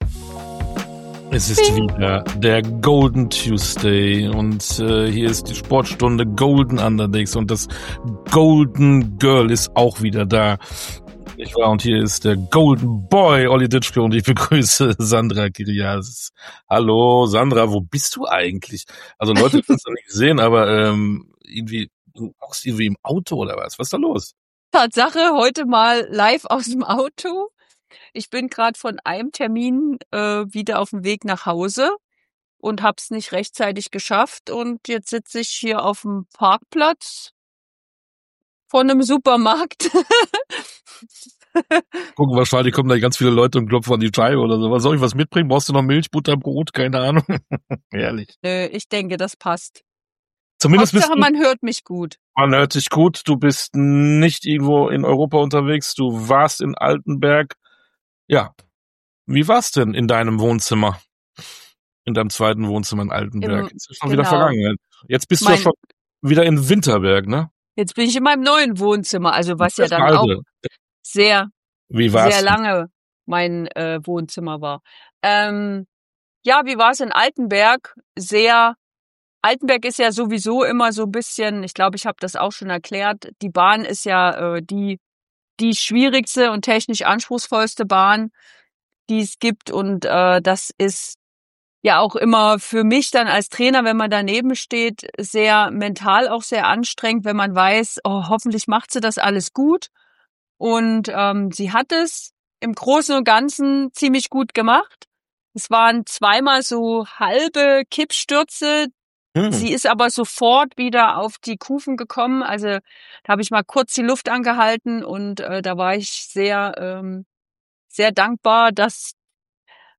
Trainerin und Olympiasiegerin Sandra Kiriasis meldet sich diesmal live vom Parkplatz – direkt zwischen zwei Weltcup-Stationen. Sie spricht über Reisen, Rennstress und die mentale Achterbahnfahrt an den anspruchsvollsten Bahnen der Welt.